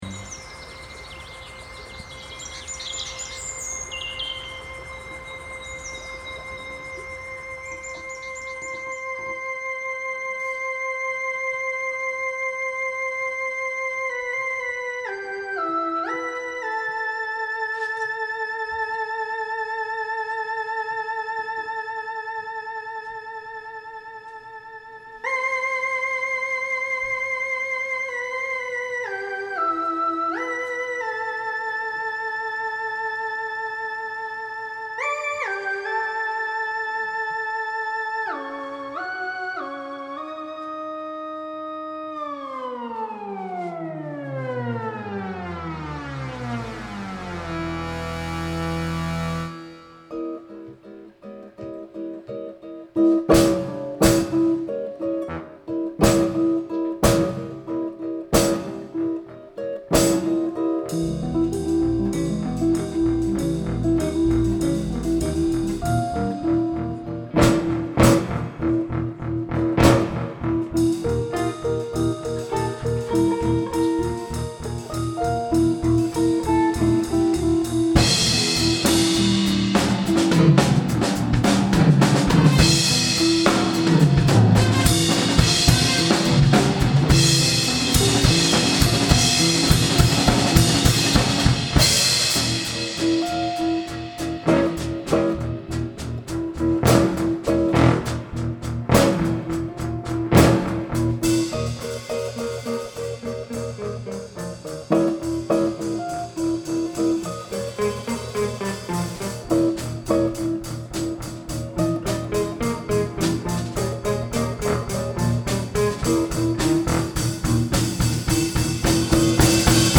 April 8, 2007 at The Box
Performed by the Orchestra